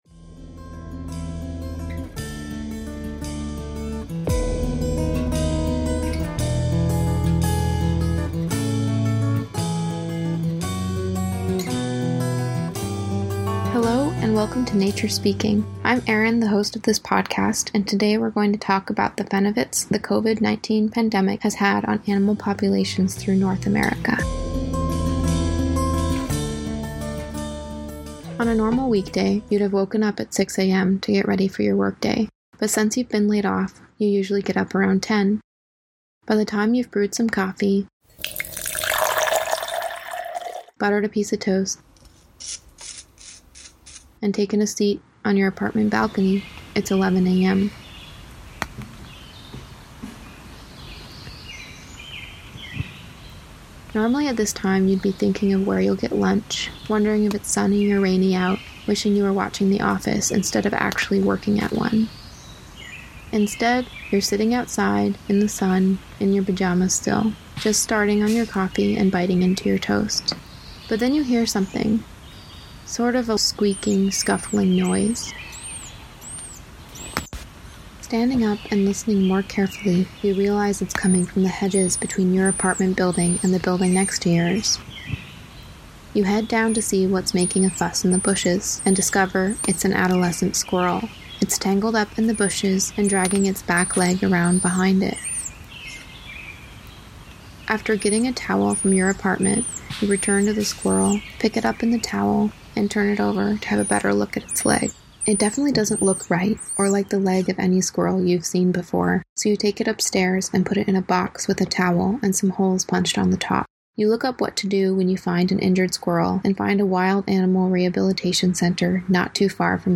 Audio non-musical